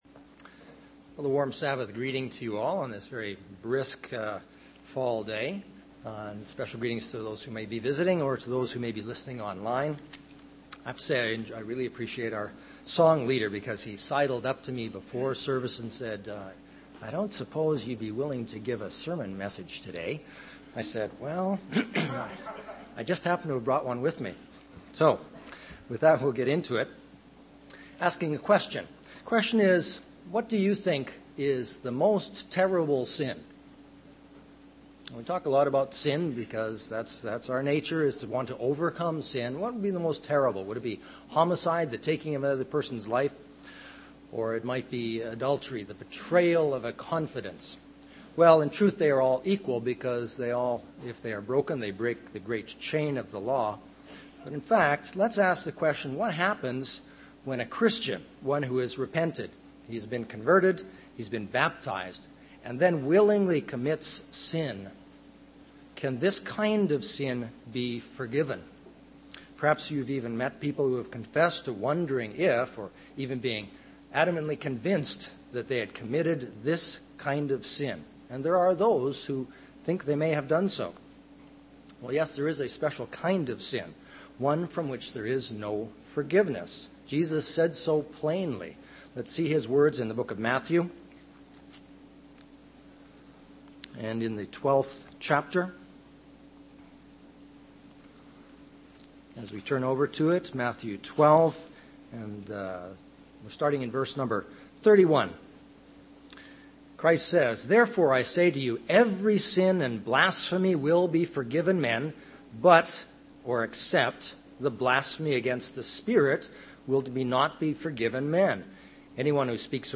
Given in Chicago, IL
UCG Sermon